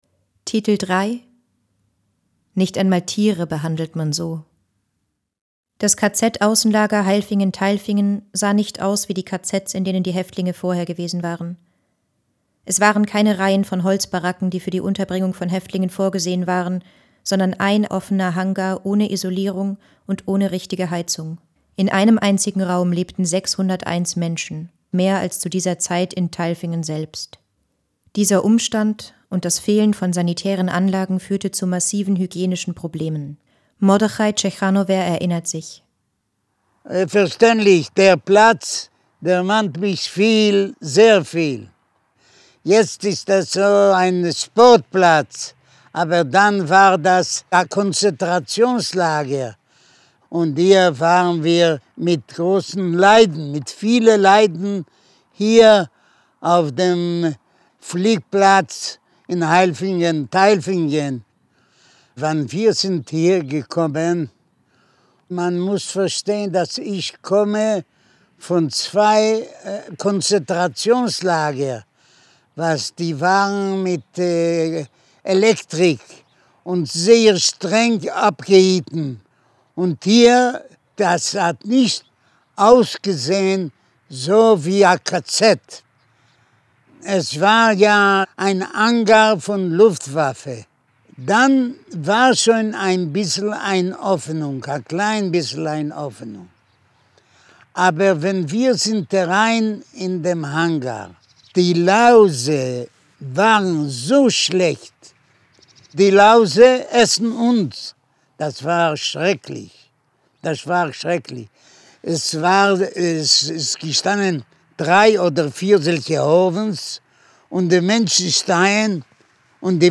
Audioguide Titel 3
hohe Audioqualität